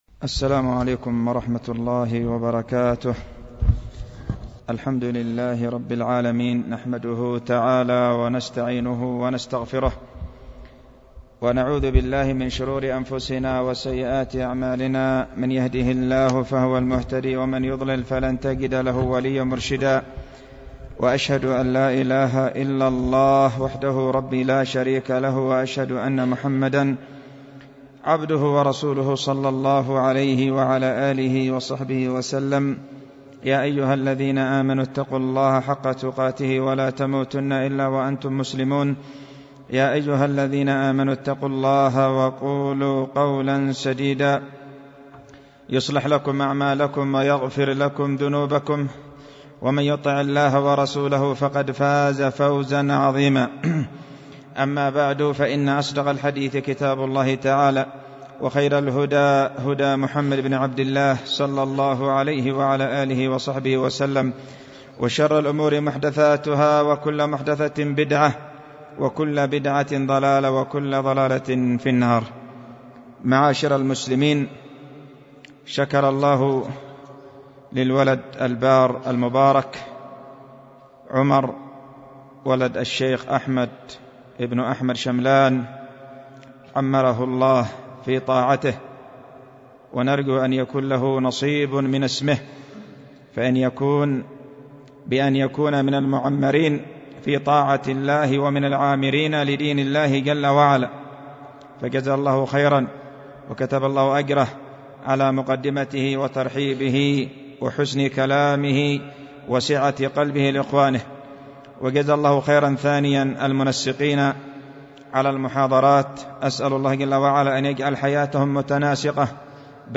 ألقيت في دار الحديث بوادي بنا السدة